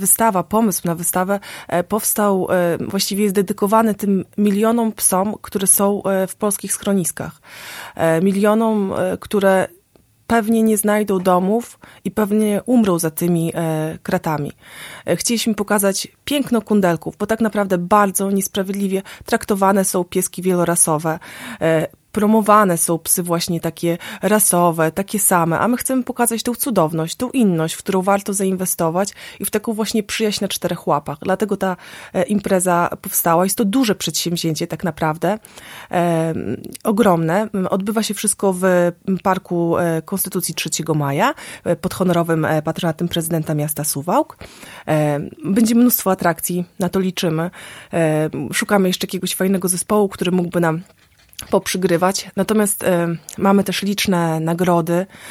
O szczegółach mówiła we wtorek (22.08)  w Radiu 5